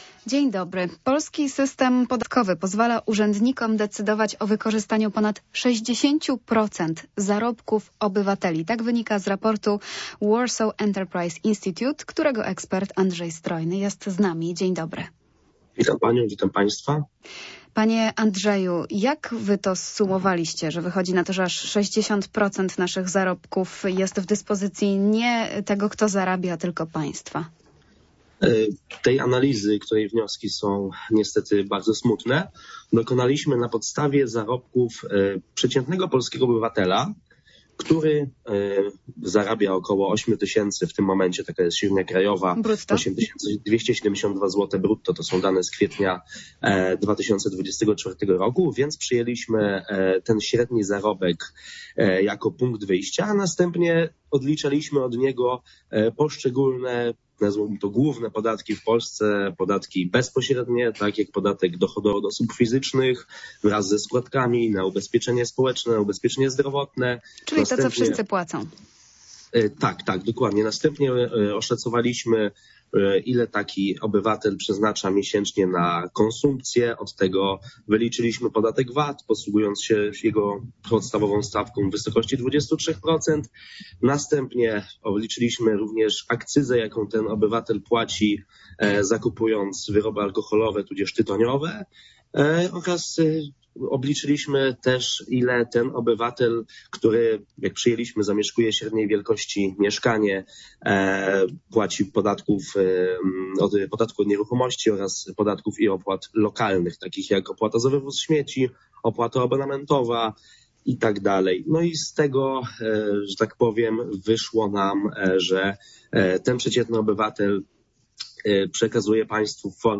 Popołudniowa rozmowa